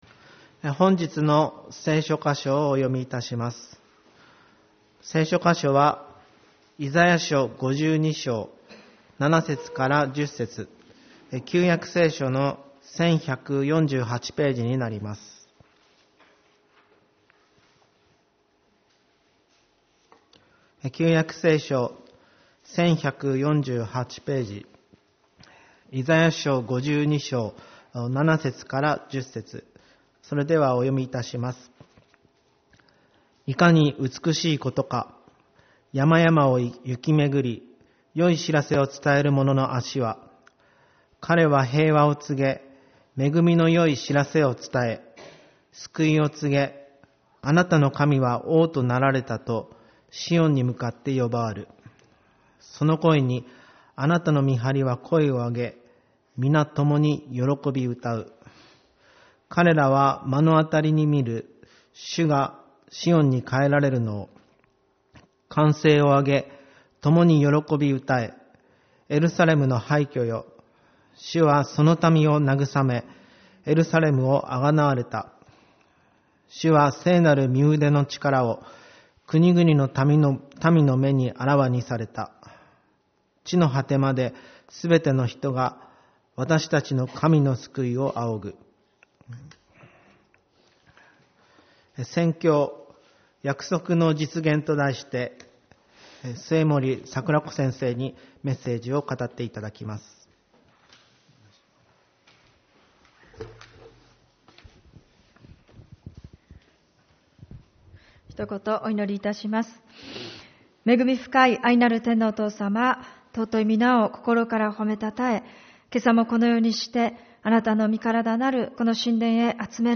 主日礼拝 「約束の実現」